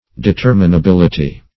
determinability - definition of determinability - synonyms, pronunciation, spelling from Free Dictionary
Search Result for " determinability" : The Collaborative International Dictionary of English v.0.48: Determinability \De*ter`mi*na*bil"i*ty\, n. The quality of being determinable; determinableness.